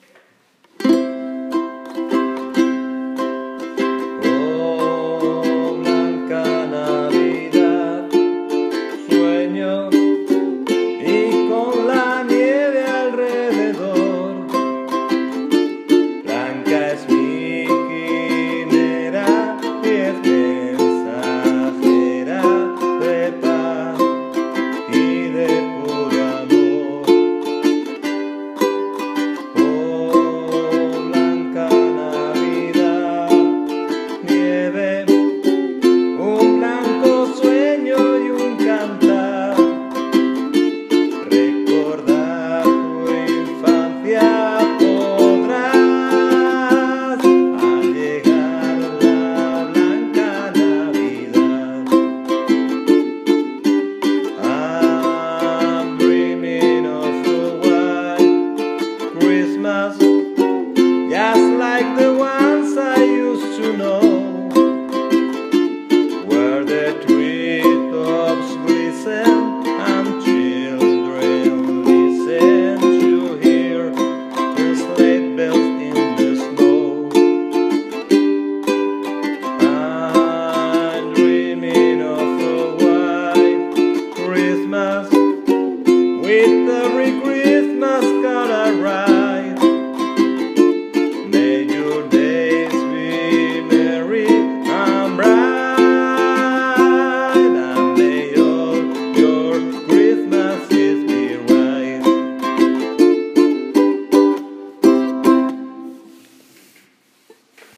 Grabación de voz y ukelele, en español e inglés.
voz y ukelele.